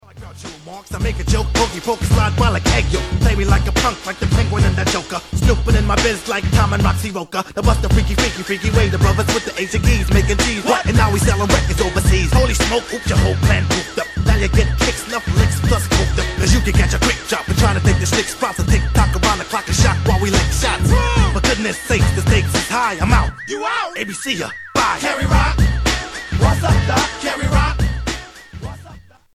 celebrity singers